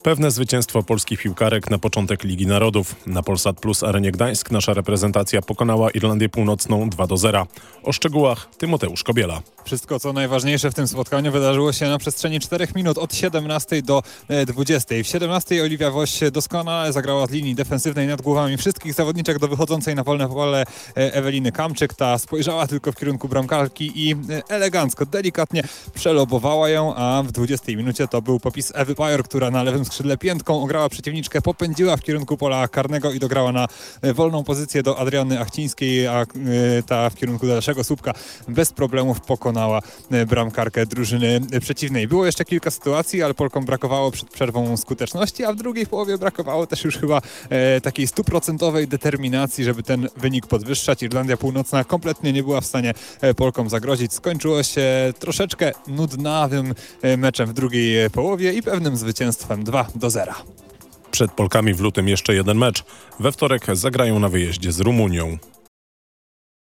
Ze stadionu nadawał